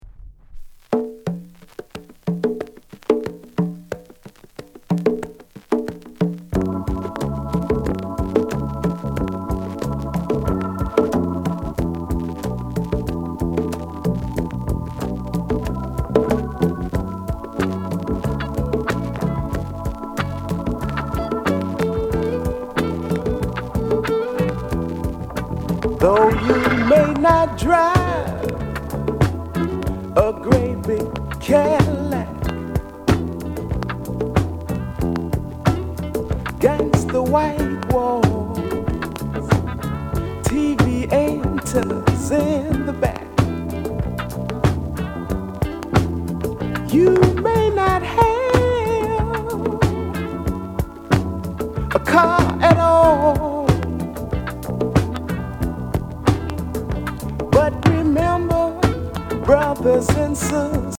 SOUL CLASSICS